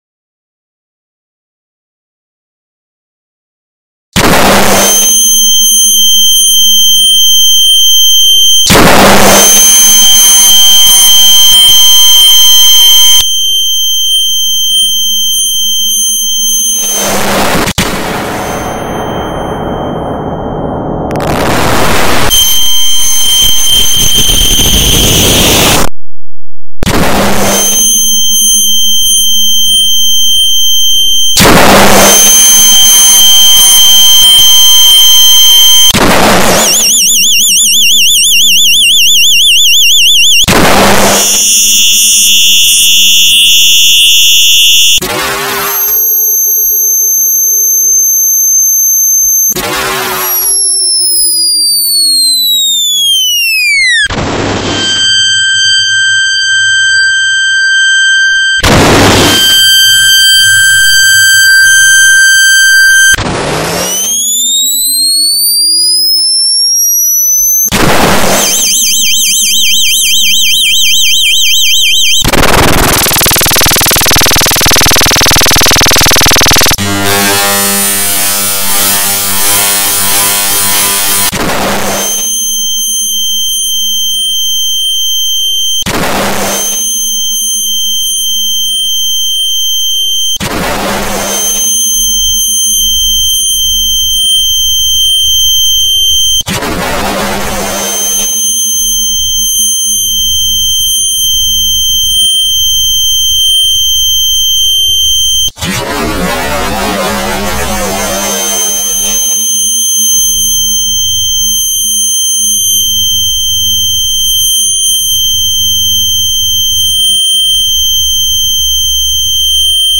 25 Flashbang Sound Variations In Sound Effects Free Download